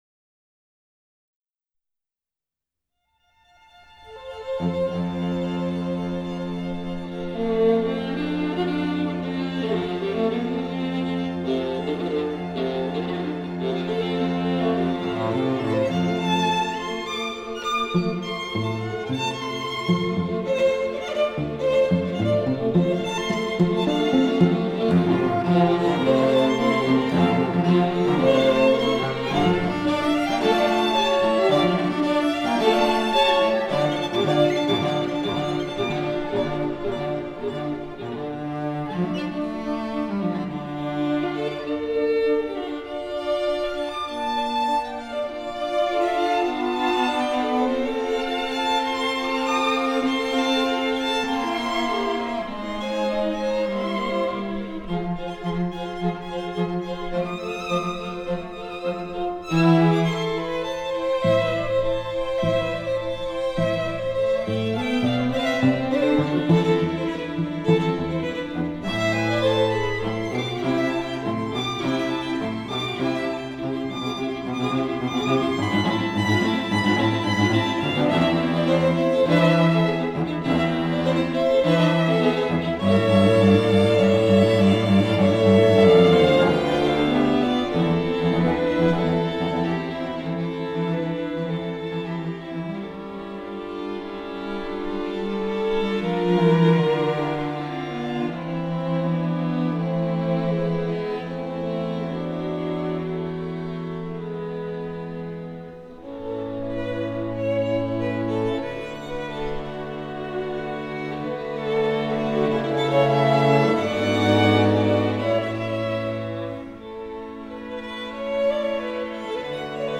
アザレア弦楽四重奏団コンサート
過去の演奏のご紹介
アザレア弦楽四重奏団